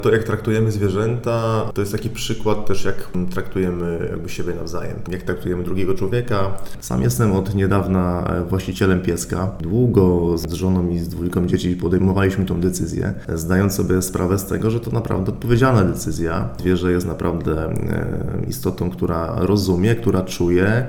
Burmistrz Iłowej, Paweł Lichtański podkreśla, że sam długo przygotowywał rodzinę do decyzji o kupnie psa.